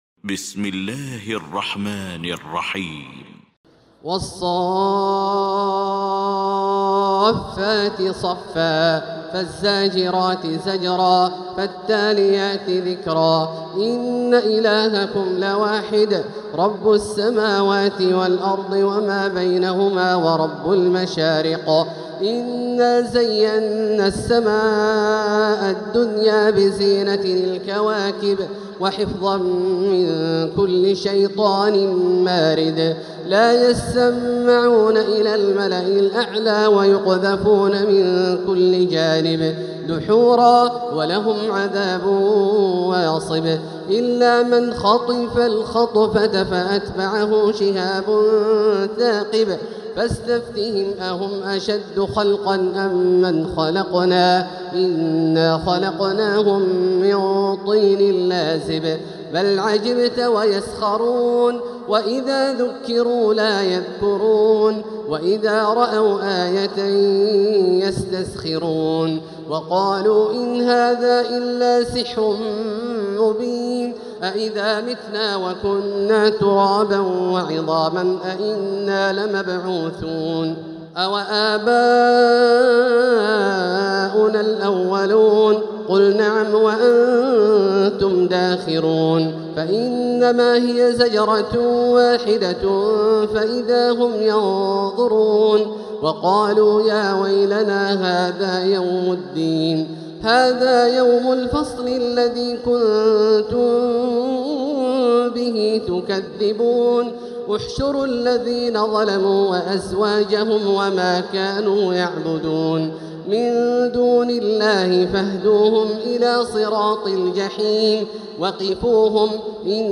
المكان: المسجد الحرام الشيخ: فضيلة الشيخ عبدالله الجهني فضيلة الشيخ عبدالله الجهني الصافات